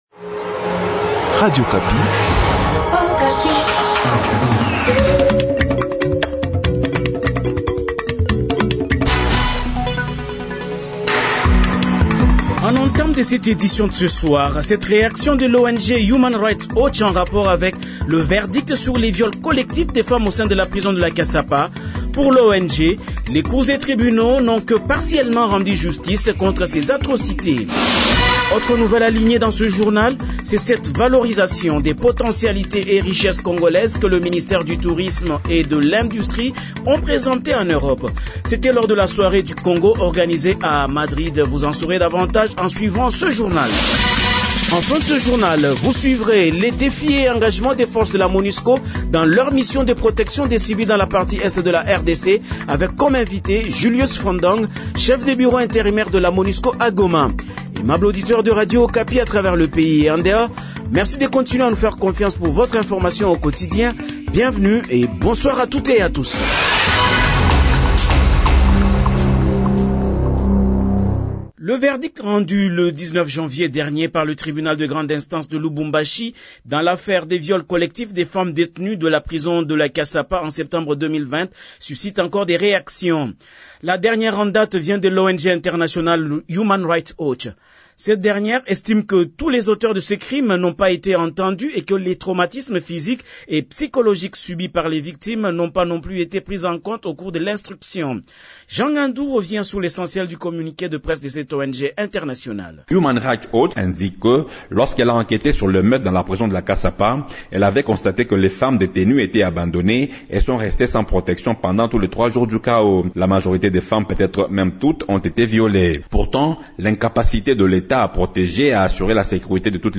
Journal Soir
Journal de 18h dimanche 23 janvier 2022